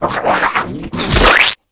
spit.wav